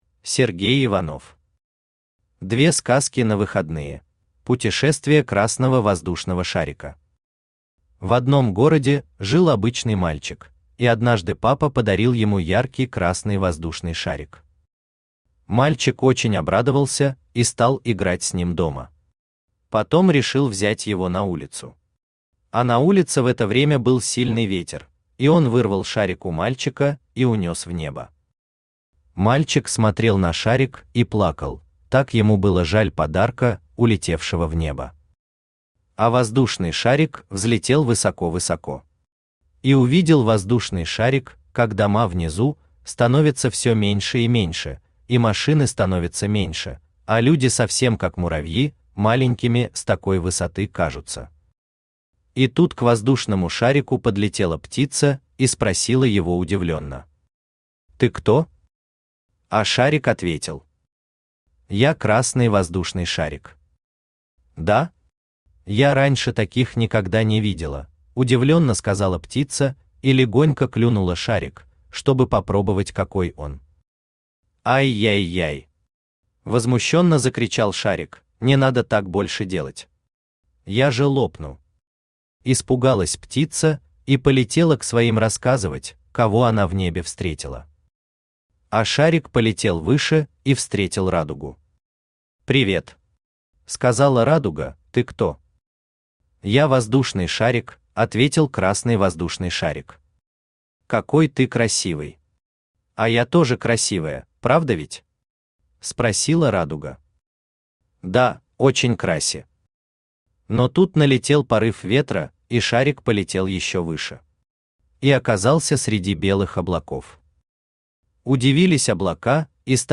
Аудиокнига Две сказки на выходные | Библиотека аудиокниг
Aудиокнига Две сказки на выходные Автор Сергей Иванов Читает аудиокнигу Авточтец ЛитРес.